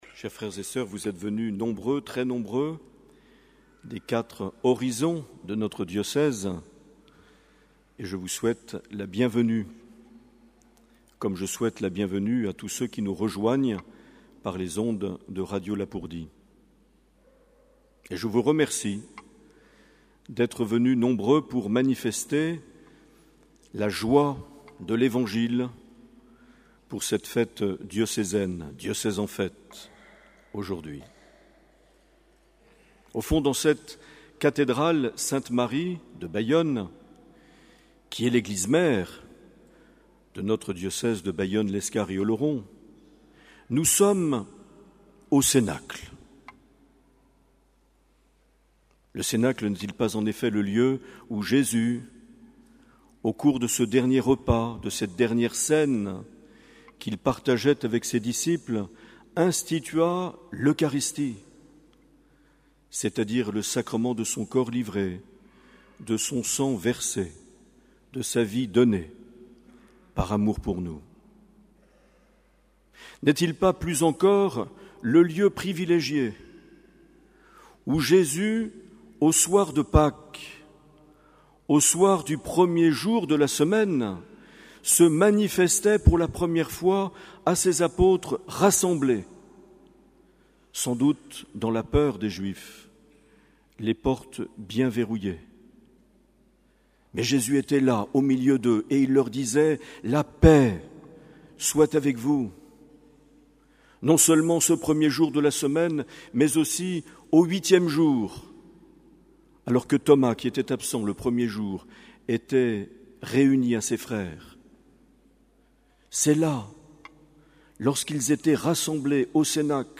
6 mai 2018 - Cathédrale de Bayonne - Diocèse en fête
Les Homélies
Une émission présentée par Monseigneur Marc Aillet